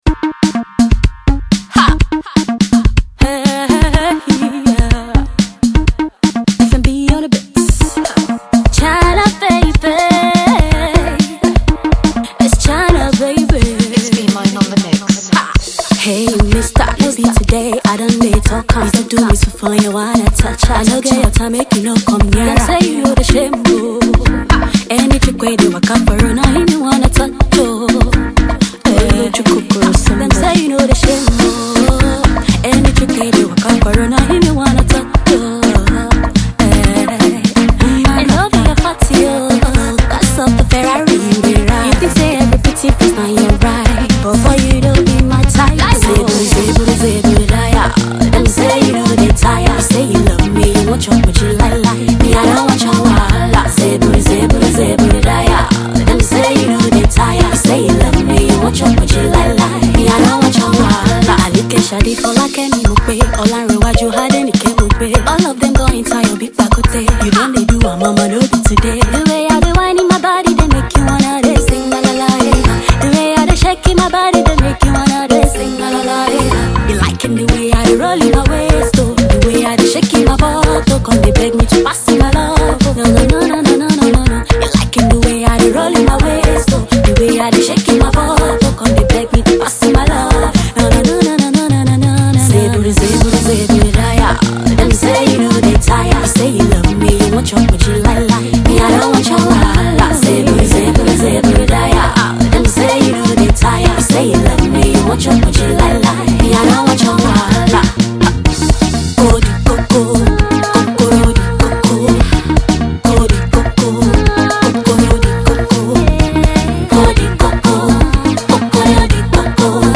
AudioPop